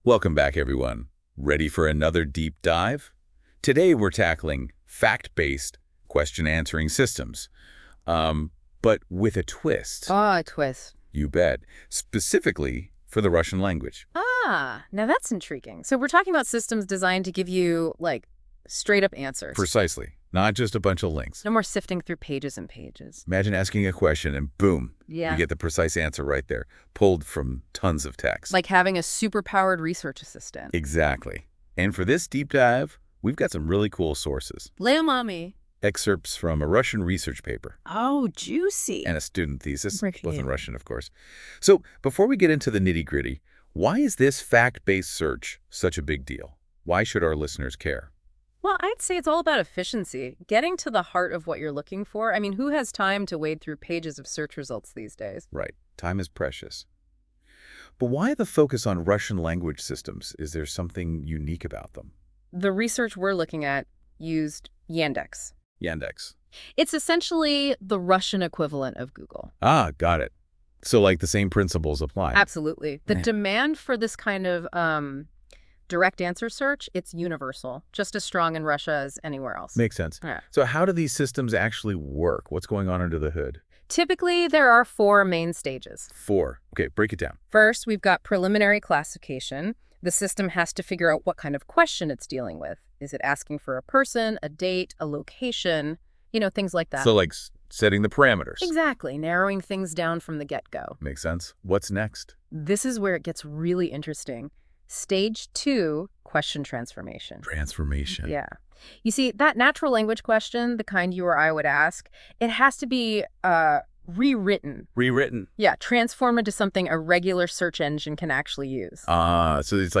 Summary Slides RCDL 2003 Paper Audio Overview (NotebookLM) Thesis (in Russian)